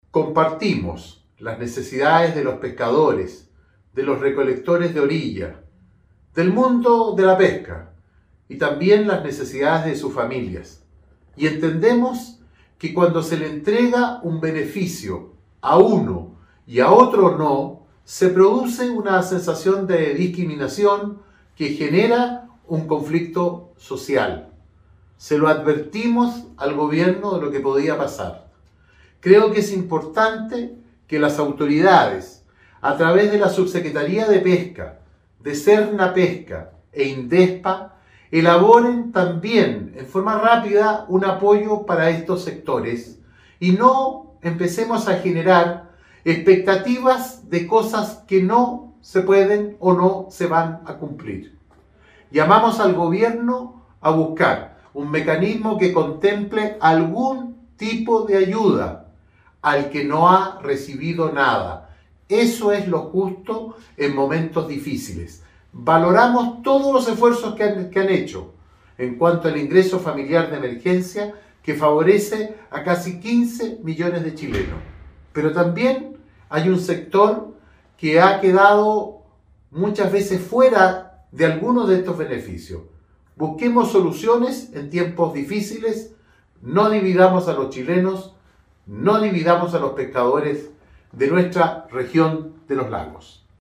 Es lo que señaló el senador Iván Moreira, quien exigió que el ejecutivo atienda los requerimientos de este gremio tan importante en la región de Los Lagos y en el país.